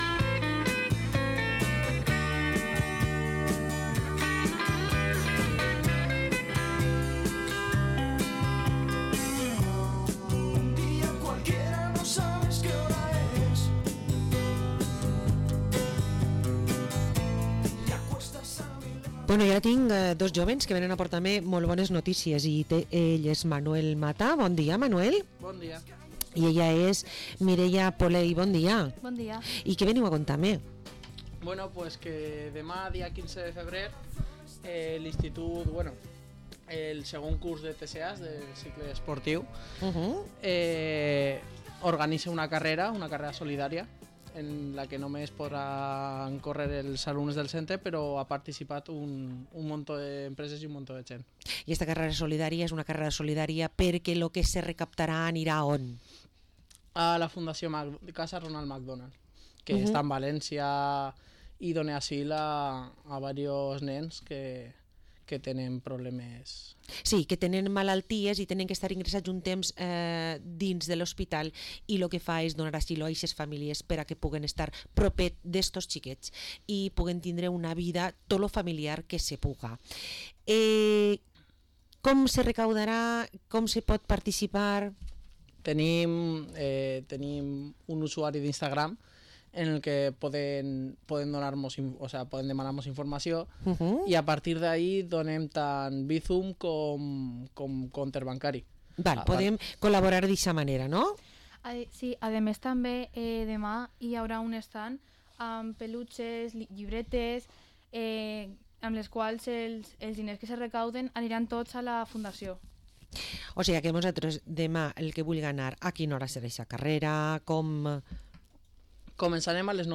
Entrevista a dos de los organizadores de la I Carrera Solidaria IES Miralcamp